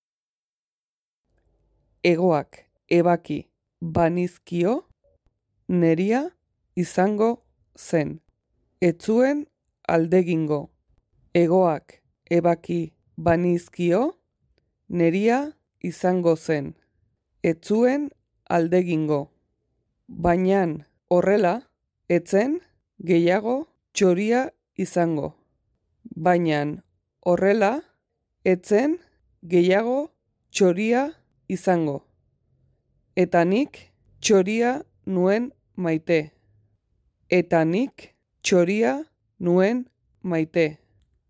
pronunciacion-nerea-izango-zen.mp3